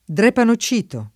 drepanocito [ dr H pano ©& to ]